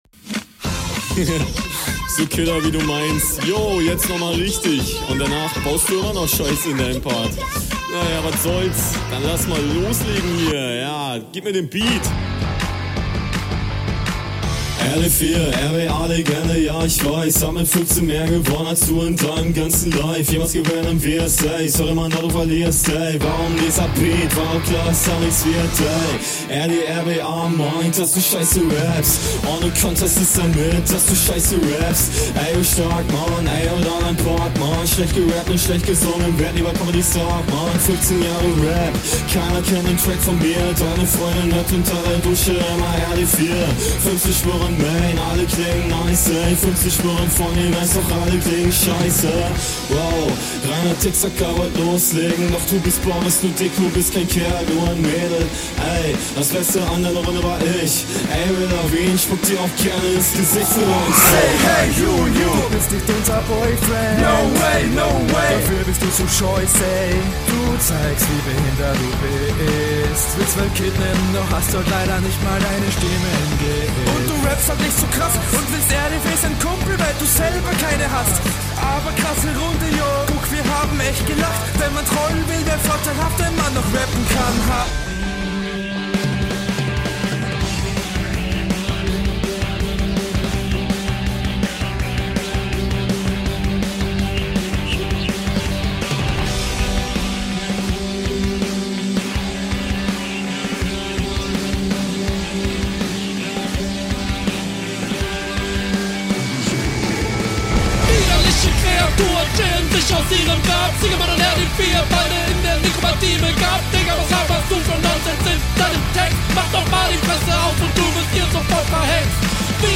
Flowlich finde ich das etwas nicer als sonst, der Stimmeinsatz ist diesmal solide, die schnelleren …
ey ey ey kopfnicker ist es schon.